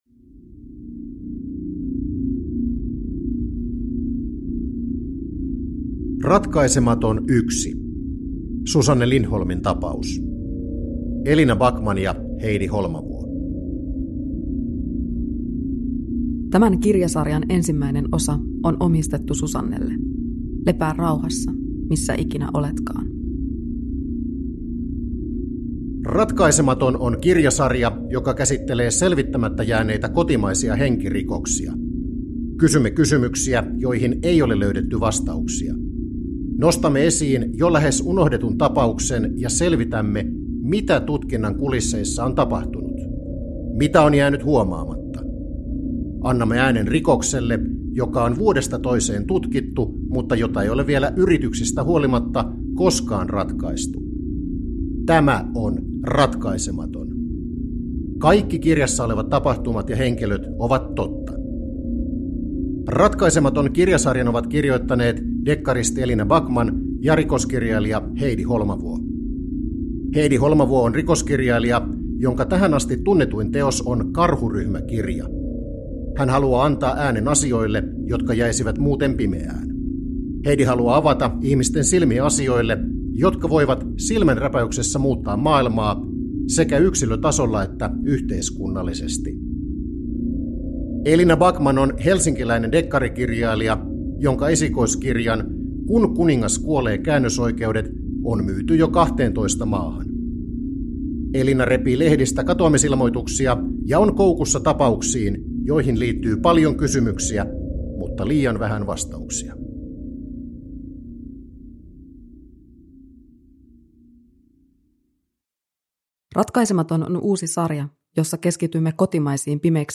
Ratkaisematon 1 (ljudbok) av Heidi Holmavuo